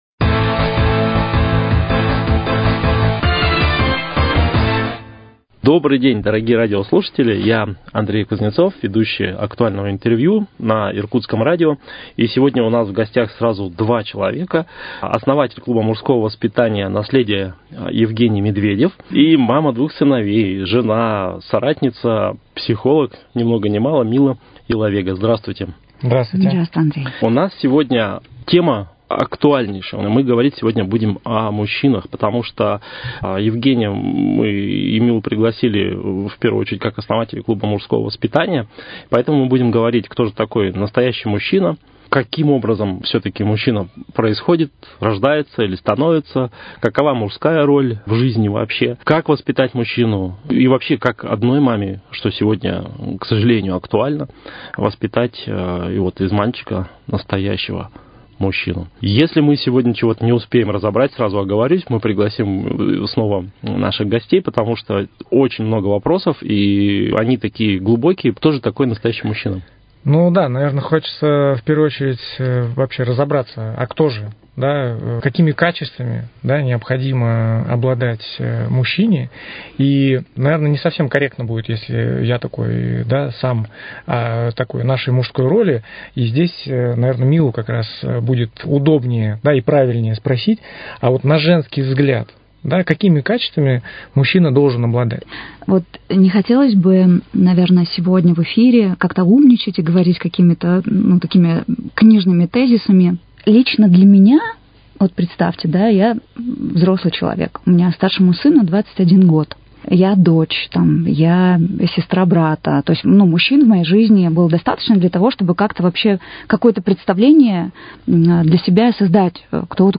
Актуальное интервью: Кто такой настоящий мужчина?